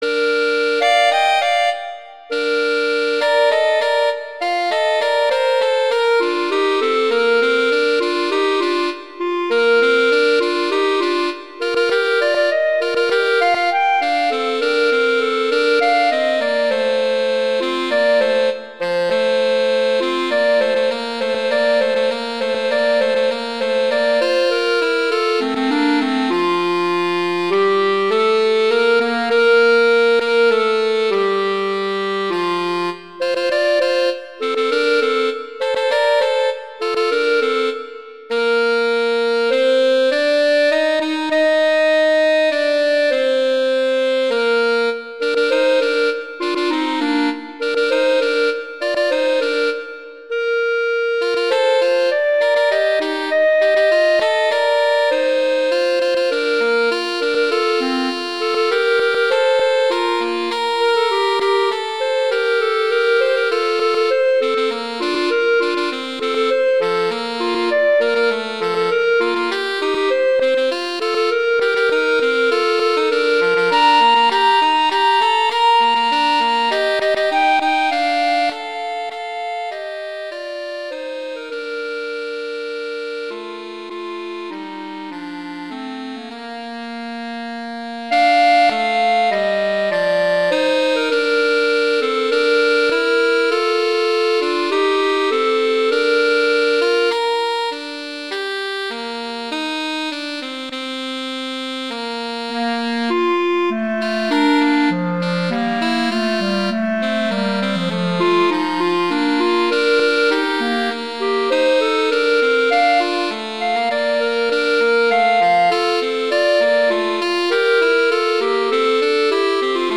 classical, sacred
Bb major
♩=120 BPM